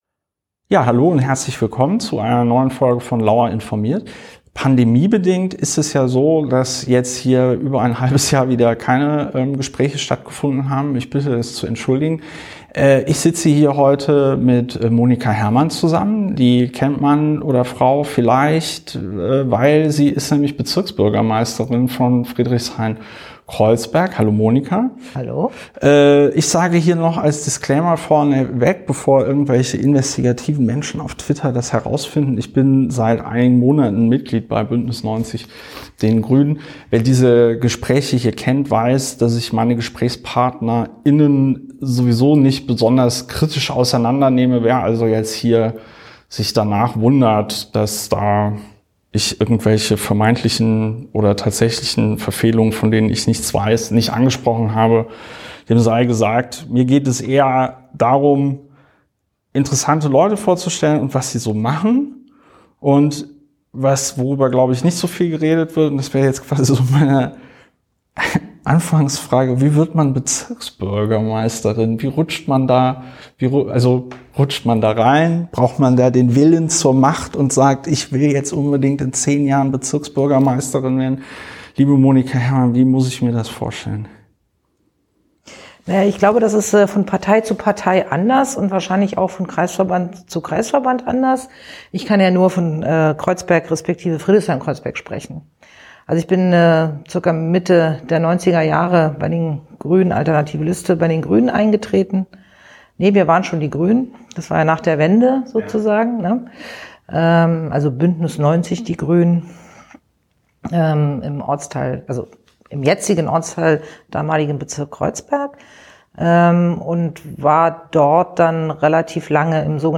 In der 134. Folge von „Lauer informiert“ spricht Christopher mit der Bezirksbürgermeisterin von Friedrichshain-Kreuzberg, Monika Herrmann (Bündnis 90/Die Grünen), darüber, wie man Bezirksbürgermeisterin wird und ob Berlin bereit ist, die Herausforderungen der Klimakatastrophe anzugehen.